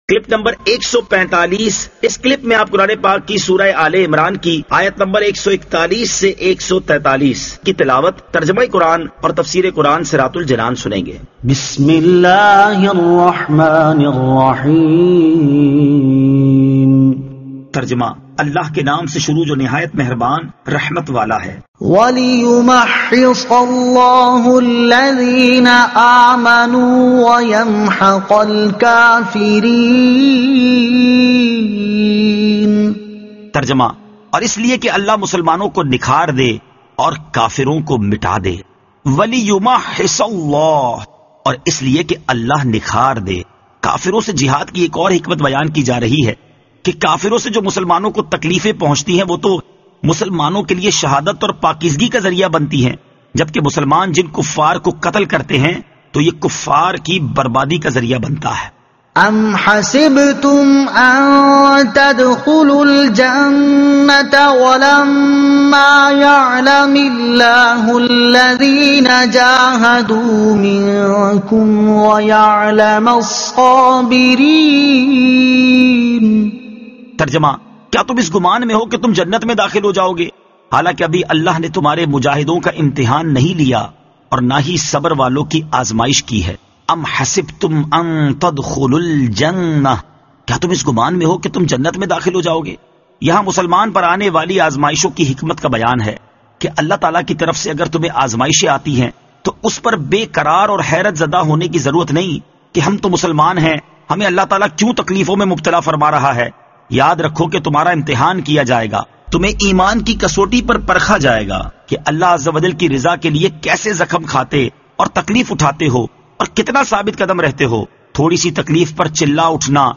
Surah Aal-e-Imran Ayat 141 To 143 Tilawat , Tarjuma , Tafseer